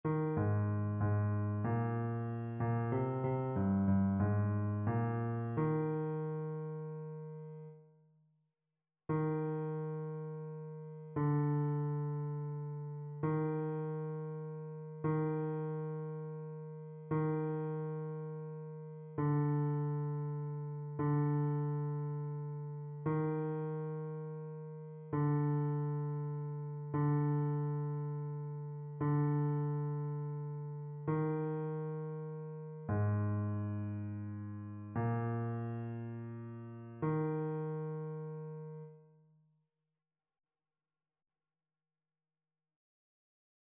Paroles : AELF - Musique : JFD
Basse
annee-c-temps-ordinaire-6e-dimanche-psaume-1-basse.mp3